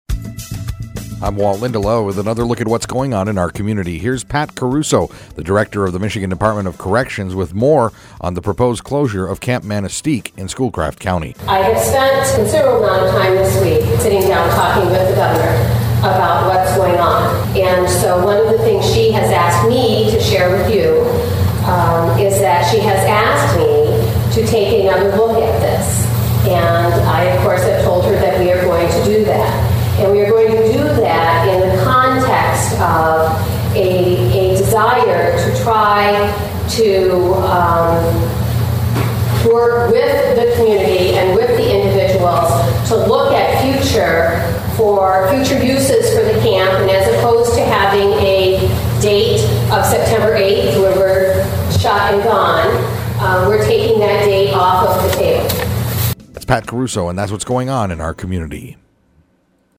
Comments taken from a public hearing held in Manistique recently to discuss the future of the Camp Manistique correctional facility.